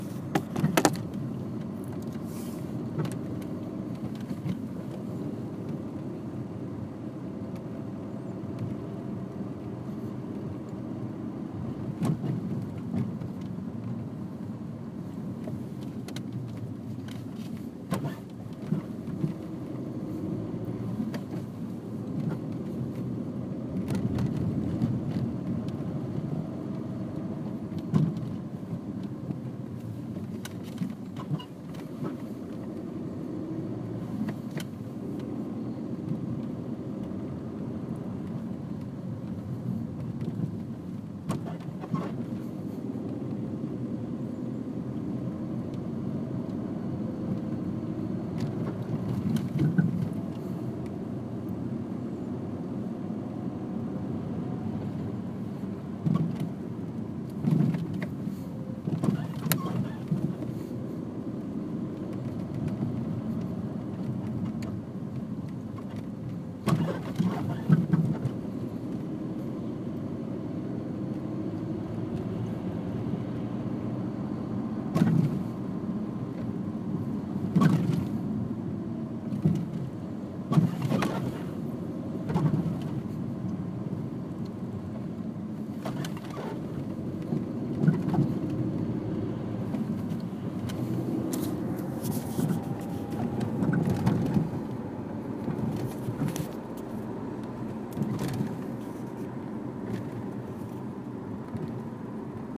Bruit Alfa Romeo.m4a
bruit-alfa-romeo.m4a